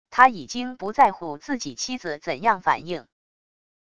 他已经不在乎自己妻子怎样反应wav音频生成系统WAV Audio Player